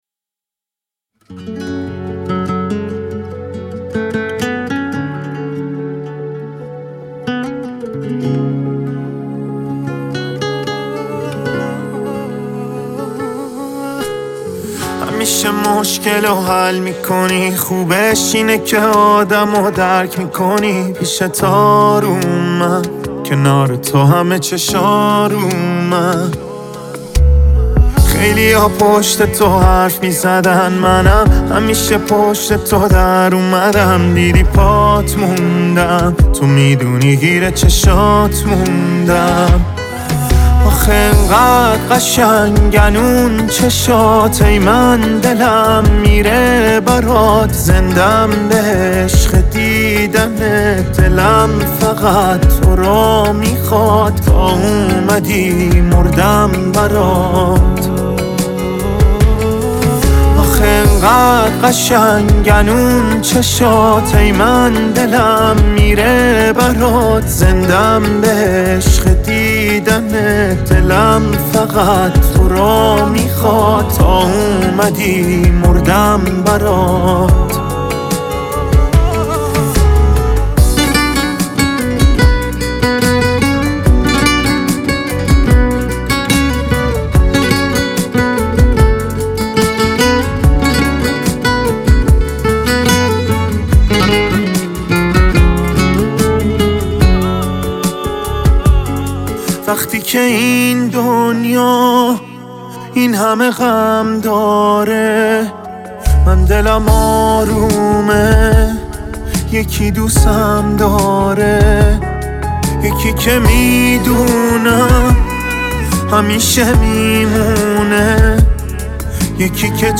خواننده پاپ
آهنگ احساسی